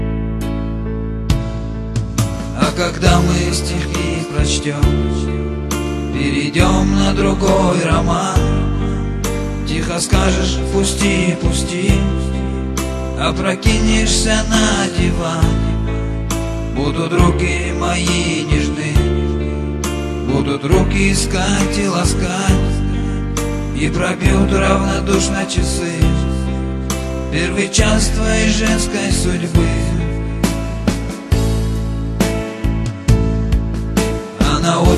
Жанр: Шансон / Русские